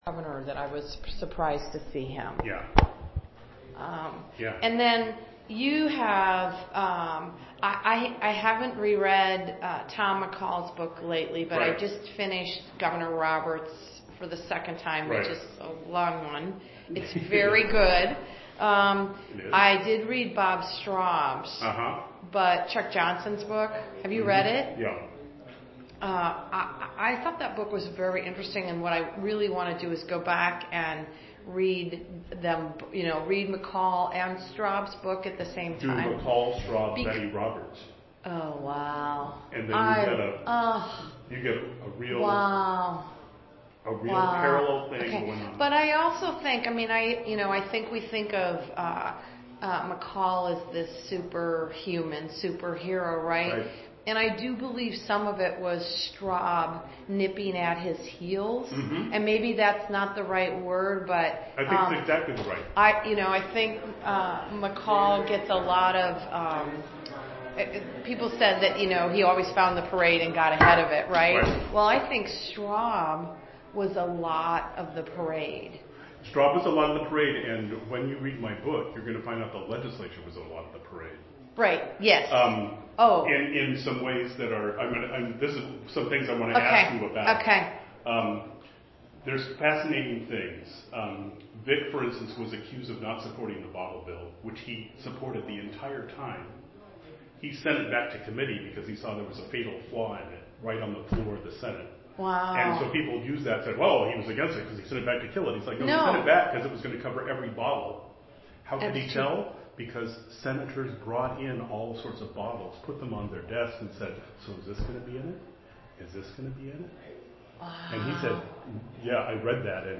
fd12e8c8d6a7aad1b36a349b3e39dc5d0beaa2d2.mp3 Title Kate Brown interview on Atiyeh Description An interview of Oregon Governor Kate Brown on the topic of former Oregon Governor Victor Atiyeh, recorded on September 2, 2016.